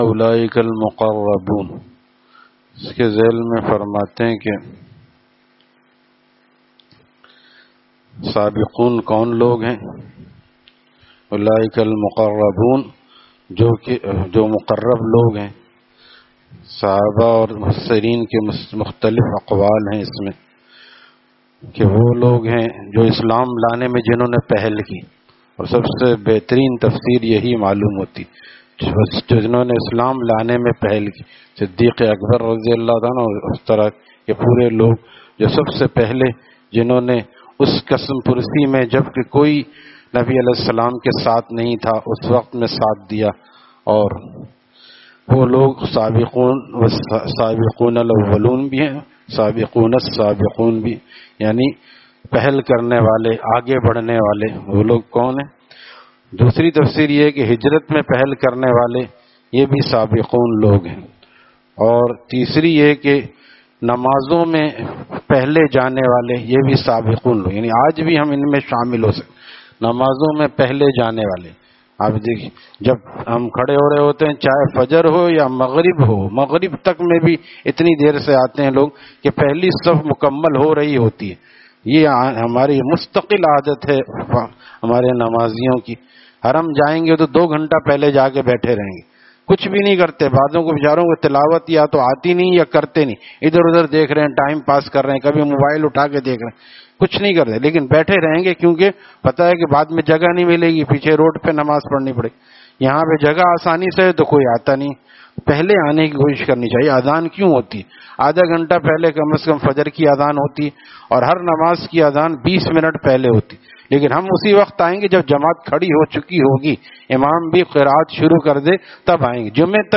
Taleem After Fajar at Jama Masjid Gulzar e Muhammadi, Khanqah Gulzar e Akhter, Sec 4D, Surjani Town